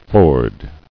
[ford]